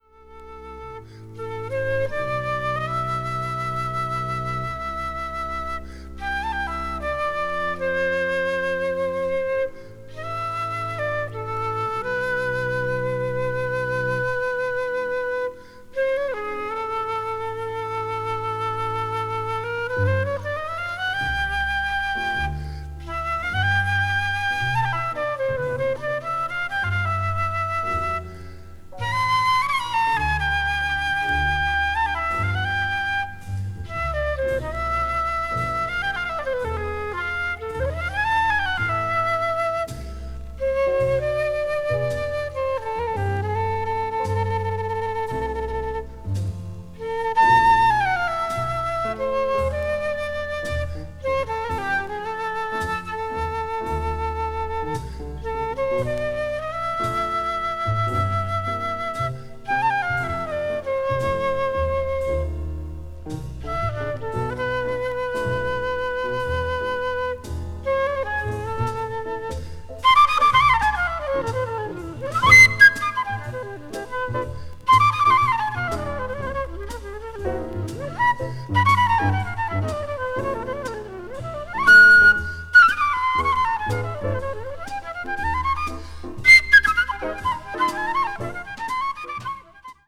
Mono.
media : EX+/EX+(わずかにチリノイズが入る箇所あり)